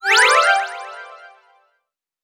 Magic_v2_wav.wav